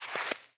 Minecraft Version Minecraft Version latest Latest Release | Latest Snapshot latest / assets / minecraft / sounds / block / powder_snow / step3.ogg Compare With Compare With Latest Release | Latest Snapshot